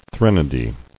[thren·o·dy]